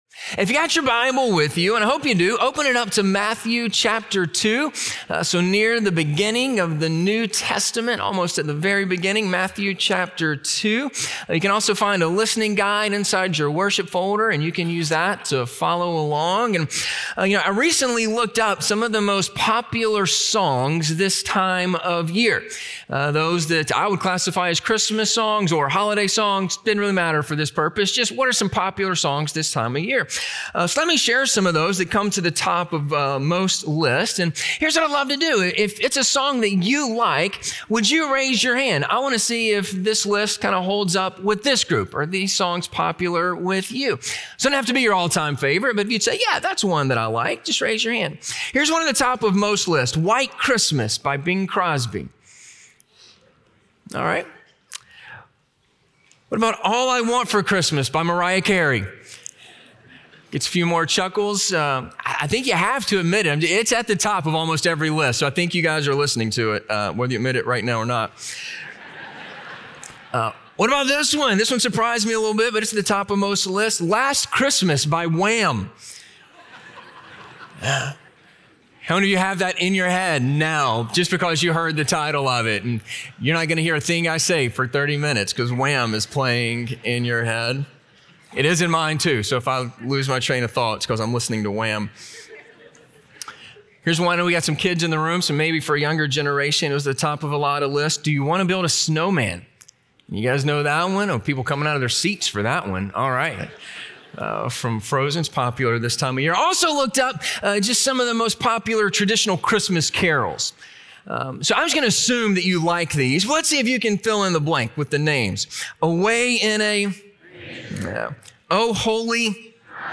Possible Responses to Jesus - Sermon - Ingleside Baptist Church